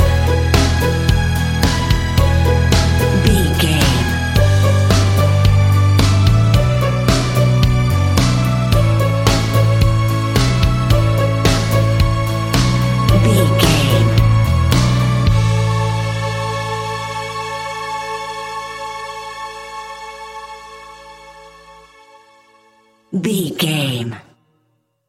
Ionian/Major
calm
melancholic
smooth
soft
uplifting
electric guitar
bass guitar
drums
strings
pop rock
indie pop
organ